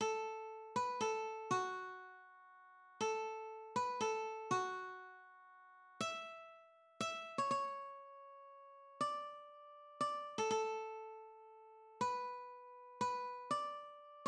\transpose c d \relative c'' { \key c \major \set Staff.midiInstrument = #"acoustic guitar (nylon)" \time 6/8 \set Score.tempoHideNote = ##t \tempo 4 = 60 \autoBeamOff g8.^"First edition" [a16] g8 e4.